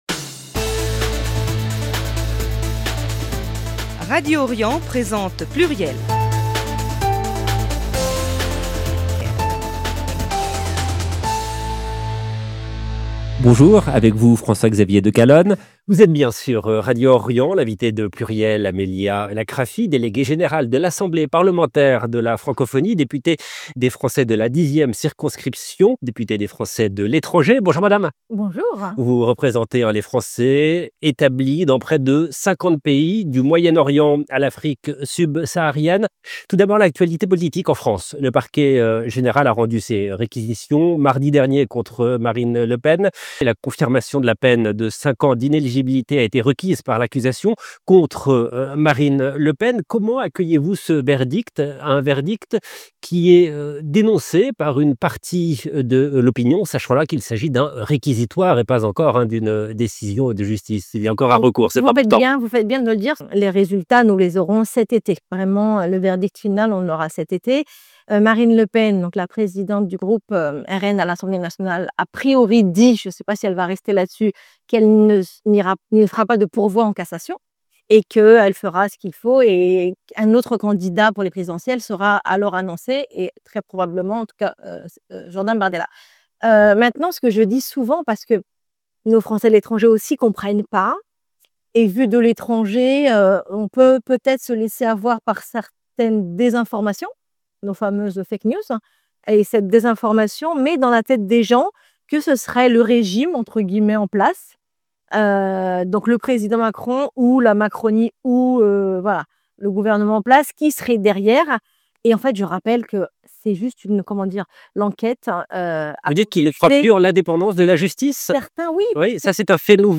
Invitée de l’émission, Amelia Lakrafi , députée des Français de l’étranger et déléguée générale de l’Assemblée parlementaire de la Francophonie , revient également sur la capacité de l’Europe à s’affirmer face aux blocs américain et chinois, ainsi que sur les moyens de protéger les prochaines échéances électorales — municipales, sénatoriales et présidentielle — contre les ingérences étrangères, notamment via les réseaux sociaux. 0:00 15 min 42 sec